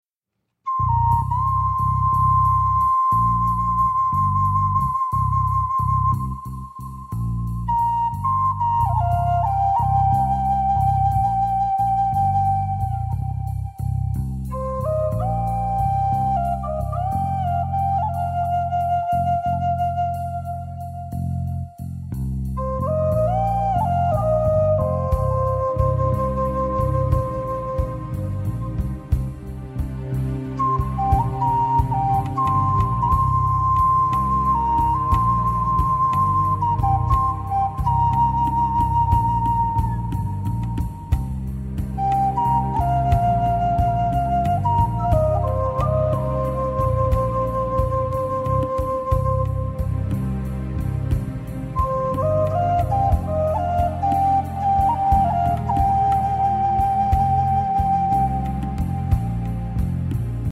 Native American Flute & Folk Music